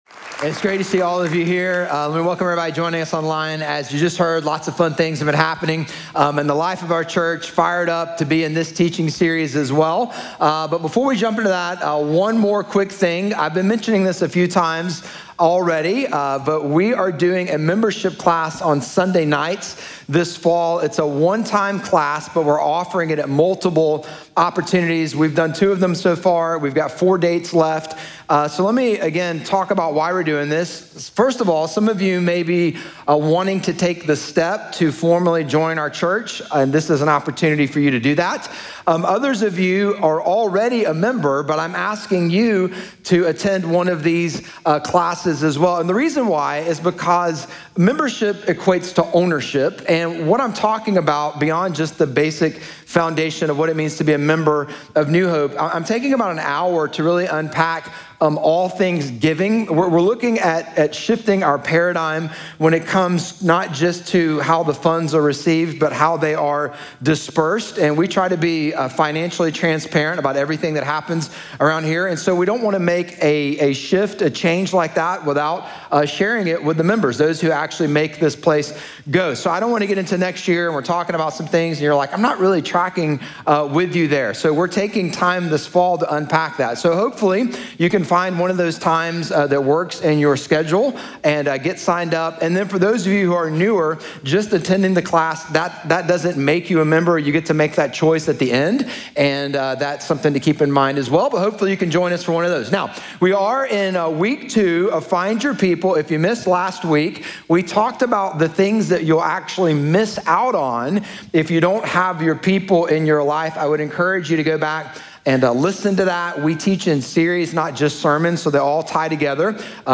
Sermon Series Podcasts
Messages from NewHope Church in Durham, NC.